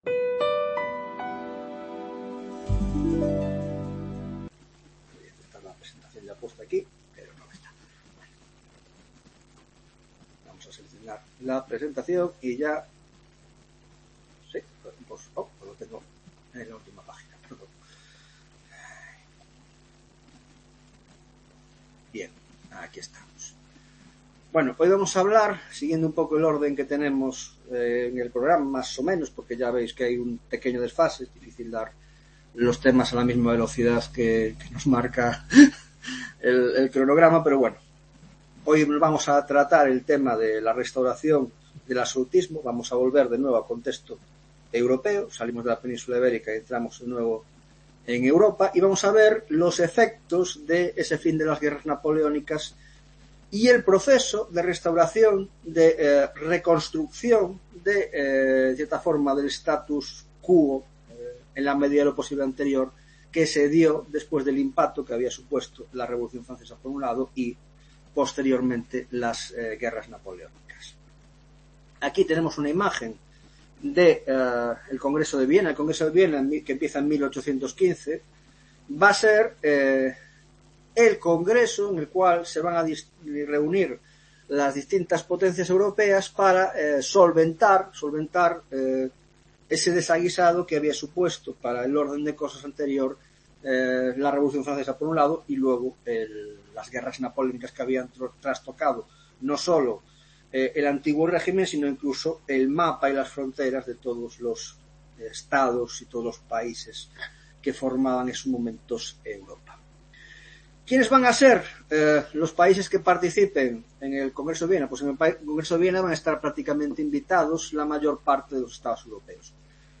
5ª tutoria de Historia Contemporánea - Congreso de Viena y el sistema de la restauración absolutista post-napoleónica; Ciclo de las Revoluciones Liberales (1820-1848)